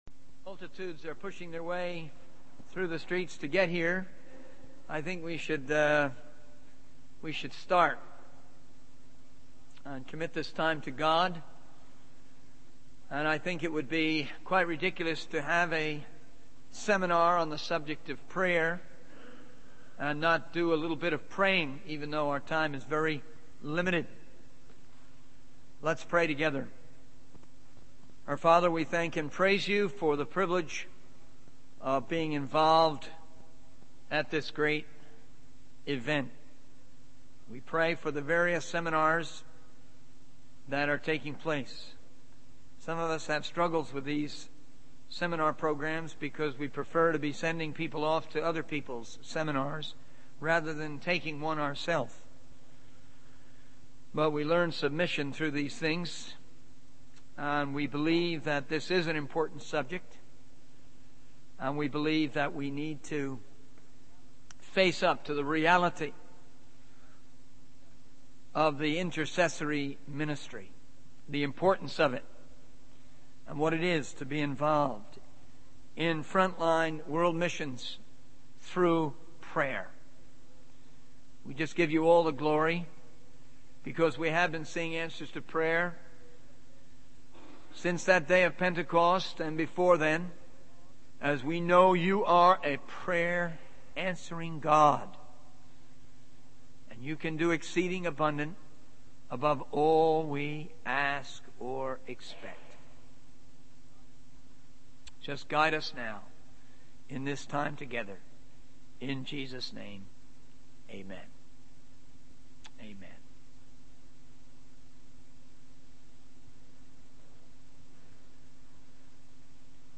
In this sermon, the speaker discusses the importance of being consistent in our Christian faith. He uses the example of Peter being imprisoned in the book of Acts to illustrate the challenges we may face in our journey.